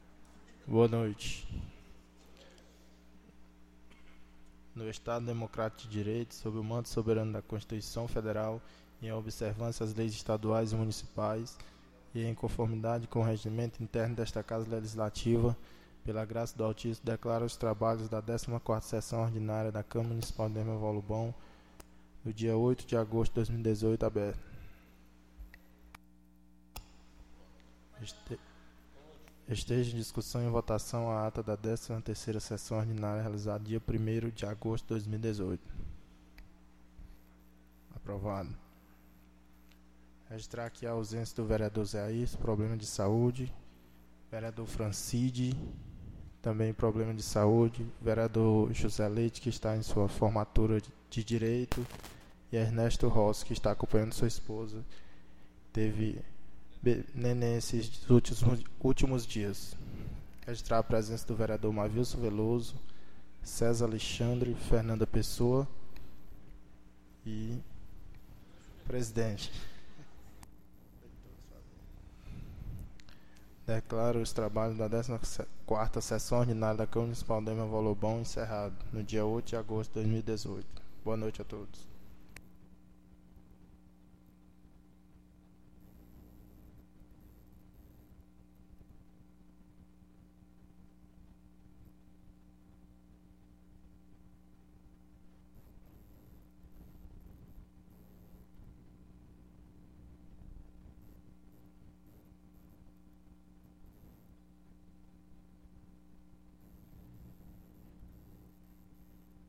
14ª Sessão Ordinária 08/08/2018